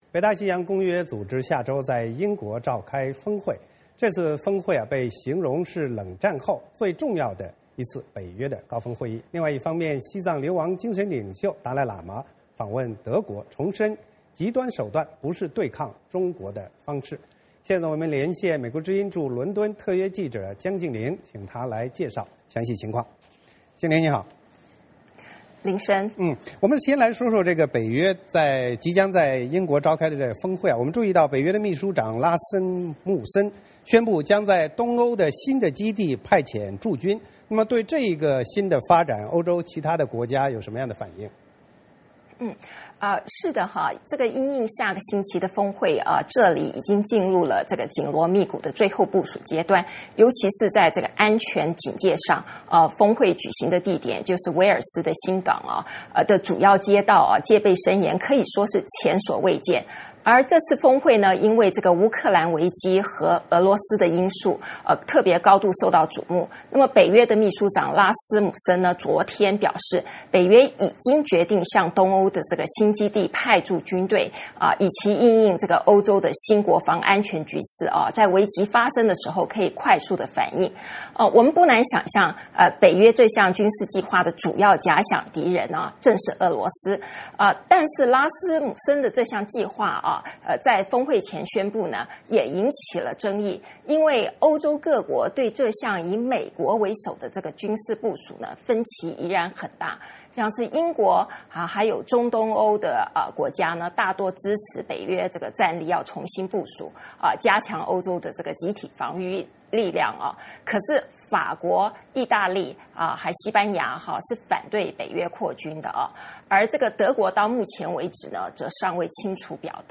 VOA连线：北约将向东欧基地派遣驻军